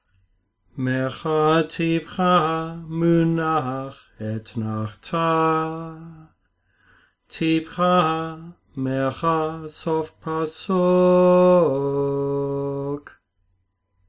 Sing with me the sequence of
seven trops repeating until it comes easily.